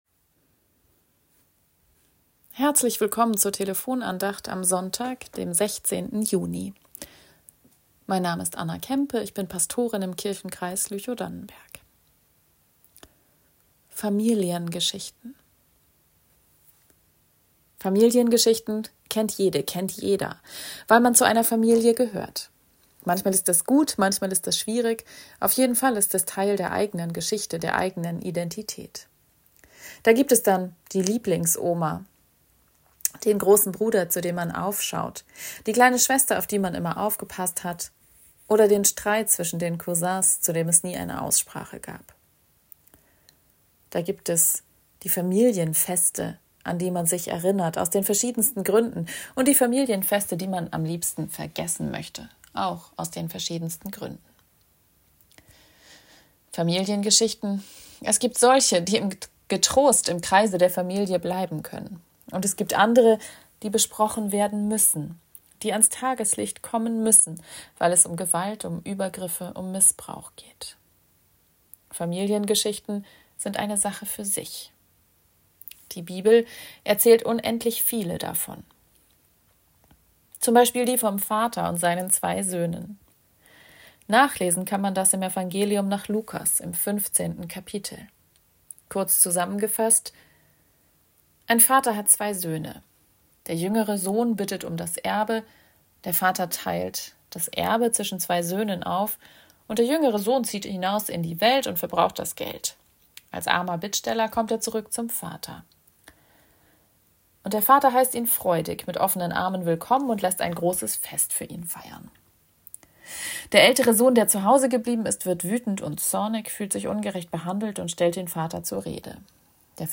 Familiengeschichten ~ Telefon-Andachten des ev.-luth. Kirchenkreises Lüchow-Dannenberg Podcast